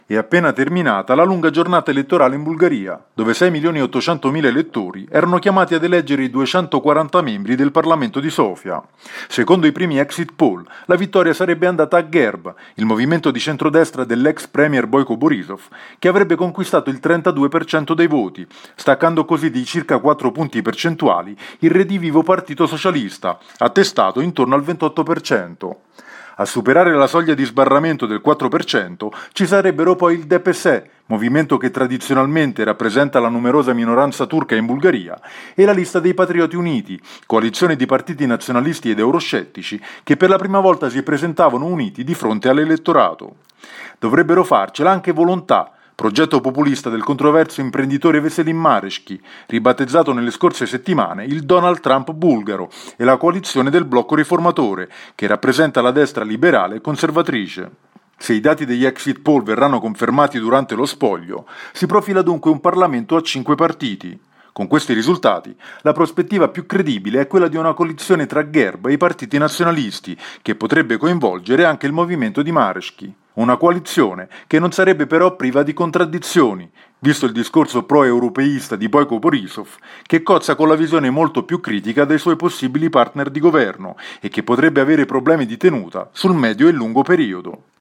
per il GR di Radio Capodistria